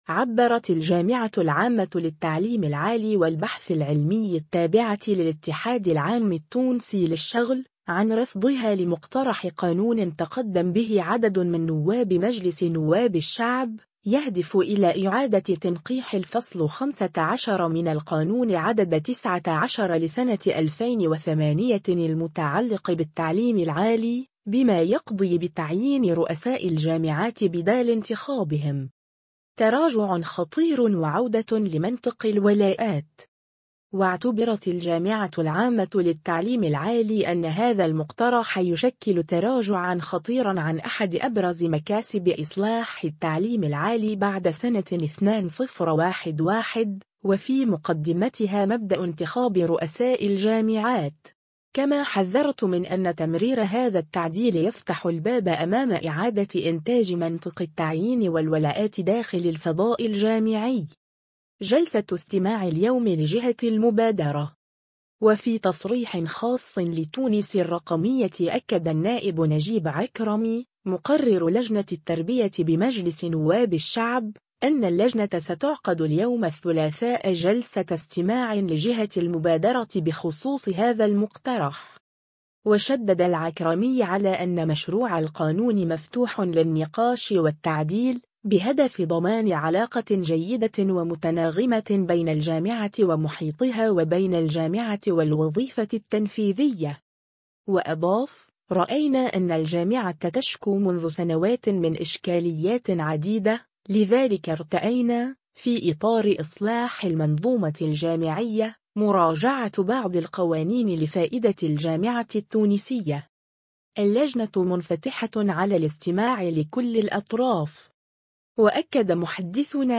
وفي تصريح خاص لـ”تونس الرقمية” أكد النائب نجيب عكرمي، مقرر لجنة التربية بمجلس نواب الشعب، أنّ اللجنة ستعقد اليوم الثلاثاء جلسة استماع لجهة المبادرة بخصوص هذا المقترح.